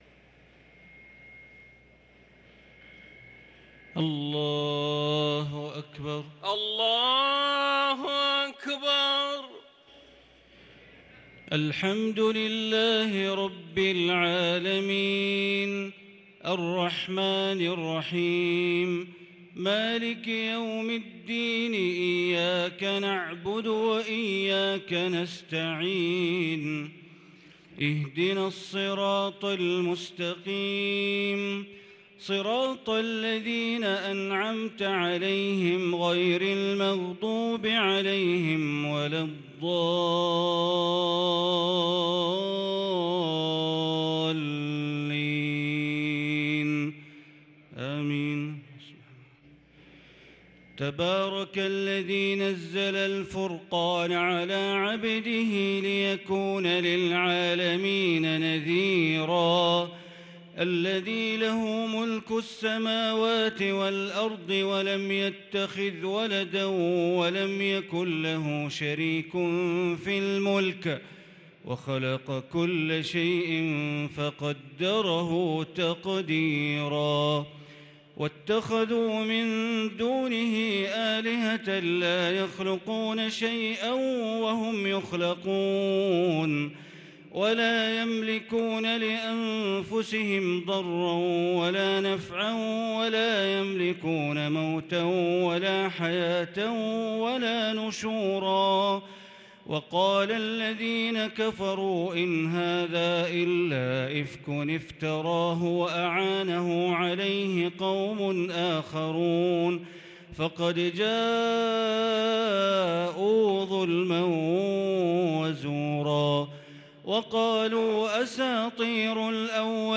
صلاة التهجّد ليلة 22 رمضان 1443هـ سورة الفرقان كاملة | Tahajjud prayer | The night of Ramadan 22 1443H | Surah Al-Furqan > تراويح الحرم المكي عام 1443 🕋 > التراويح - تلاوات الحرمين